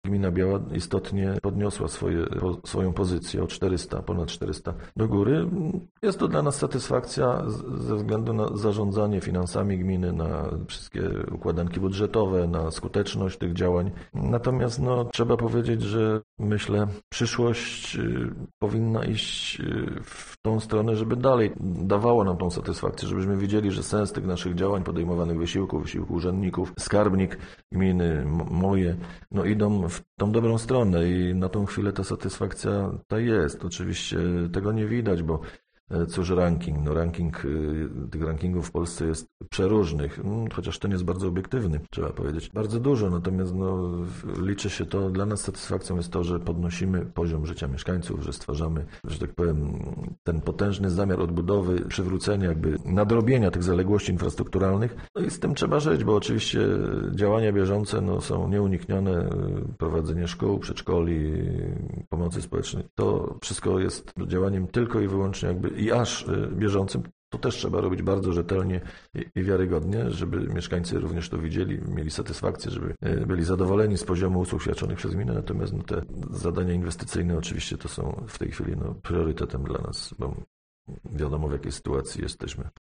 Mówi Aleksander Owczarek, wójt gminy Biała: Biała podniosła swoją pozycję o ponad 400.